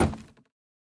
Minecraft Version Minecraft Version snapshot Latest Release | Latest Snapshot snapshot / assets / minecraft / sounds / block / bamboo_wood_hanging_sign / step4.ogg Compare With Compare With Latest Release | Latest Snapshot
step4.ogg